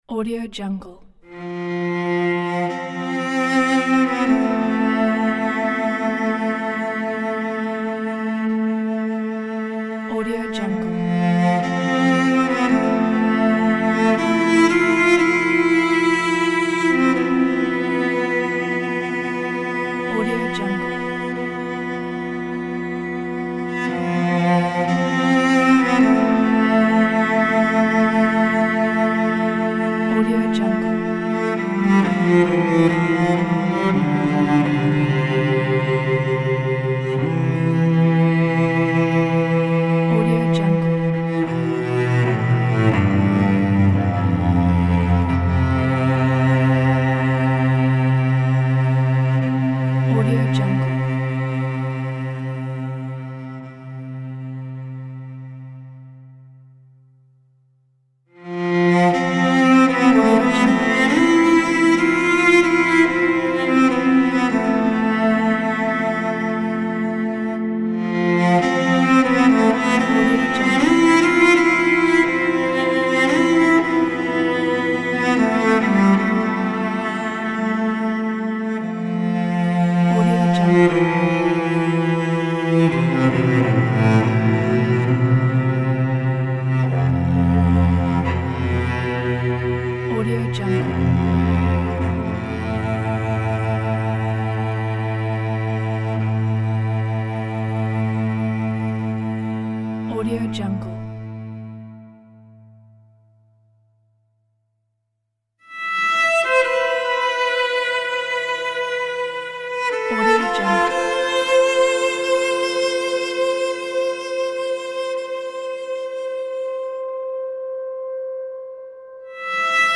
آمبیانس و آرام